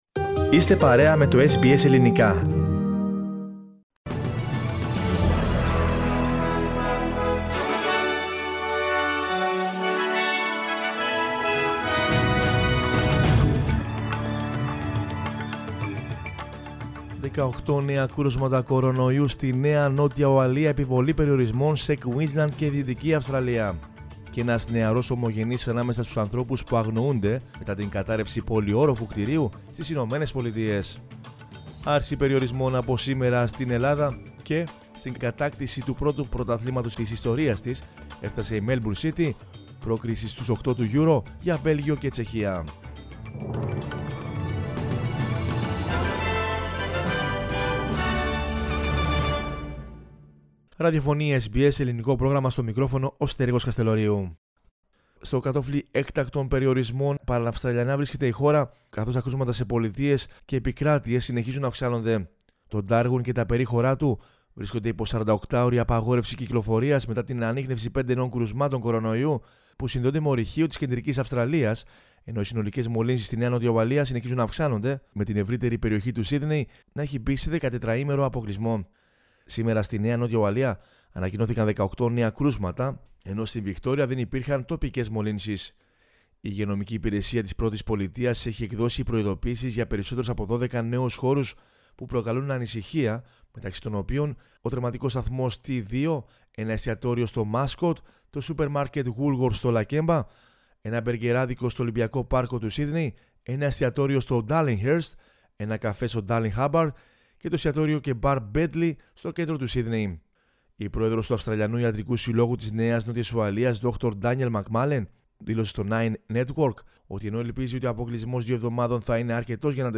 News in Greek from Australia, Greece, Cyprus and the world is the news bulletin of Monday 28 June 2021.